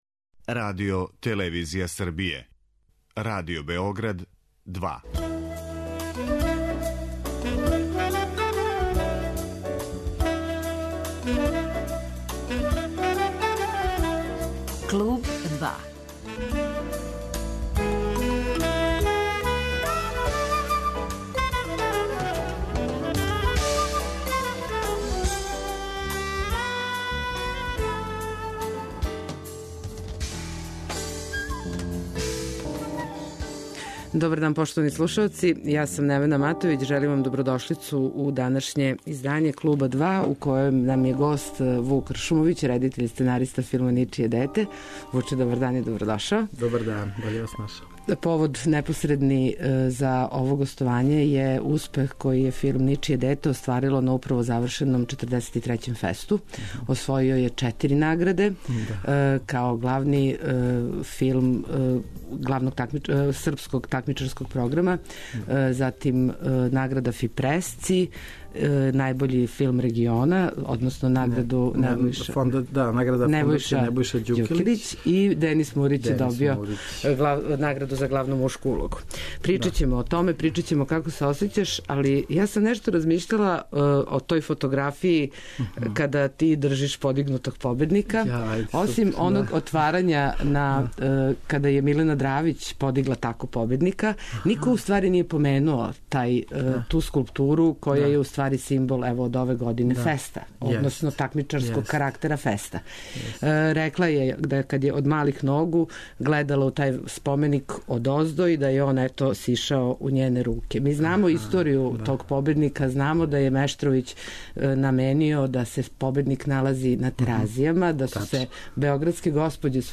[ детаљније ] Све епизоде серијала Аудио подкаст Радио Београд 2 Сомерсет Мом: Паранг Мери Е. Брендон: Добра госпа Дукејн Андрија Мауровић: Тројица у мраку Жан Кокто: Антигона Срђан Вучинић: Драгутин Илић